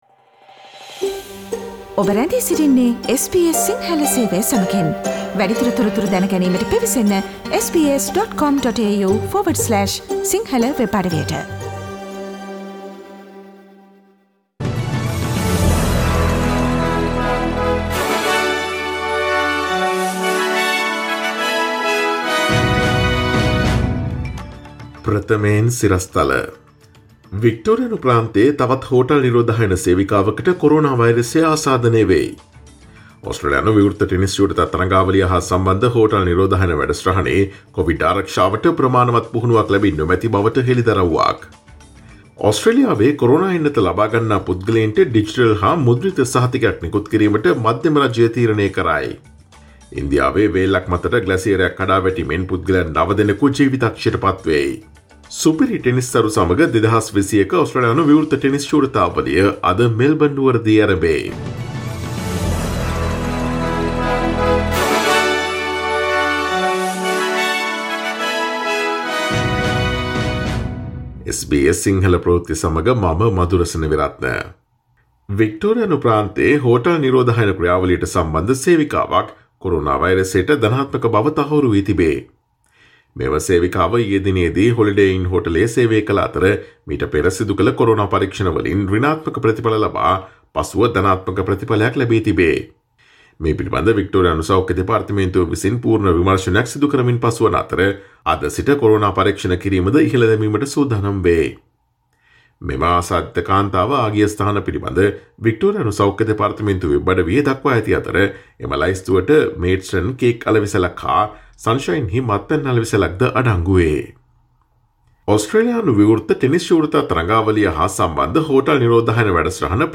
Today’s news bulletin of SBS Sinhala radio – Monday 08 February 2021.